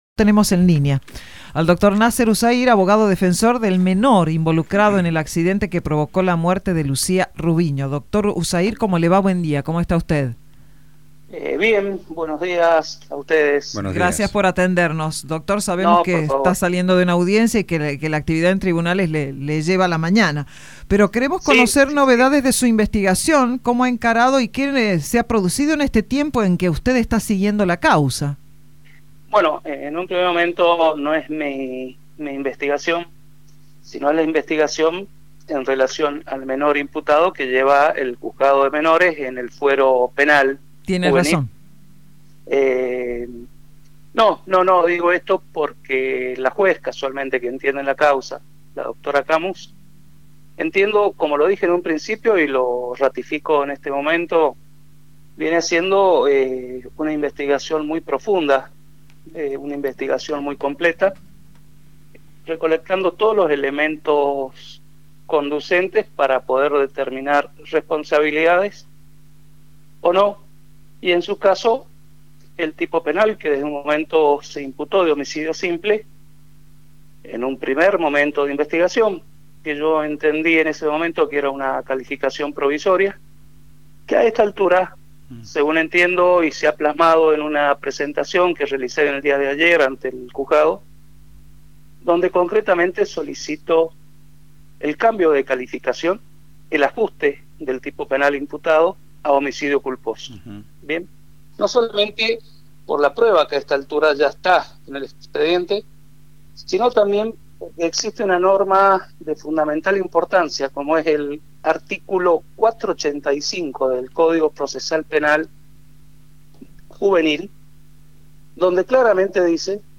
En una entrevista en vivo con LV5 Sarmiento